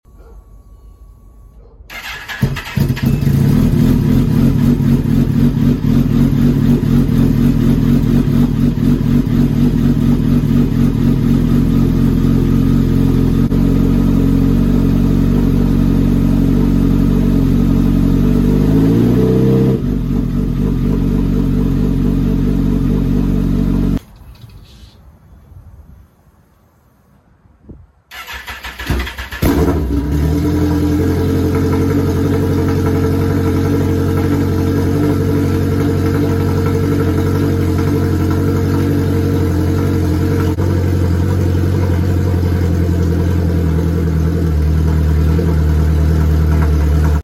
Switched from Brock’s to a voodoo sidewinder big tube. Sounds good to me.